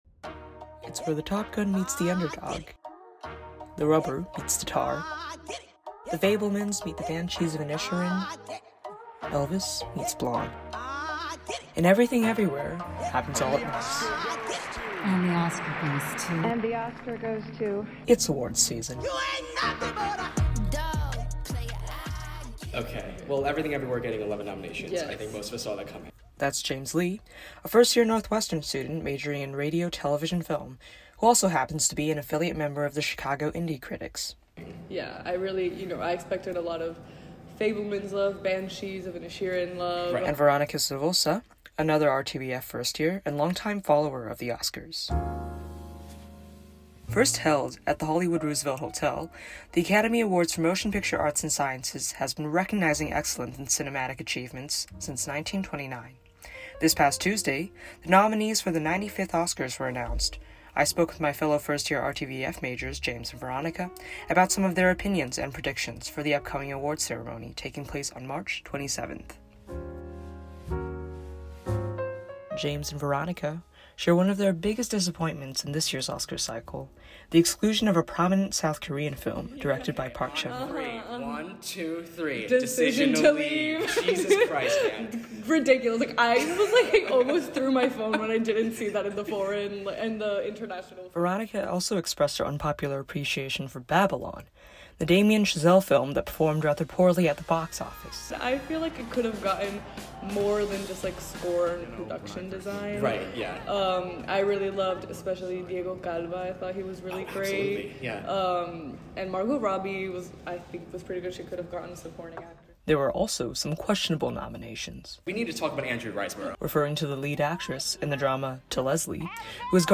The 95th Academy Award Nominations Roundtable – WNUR News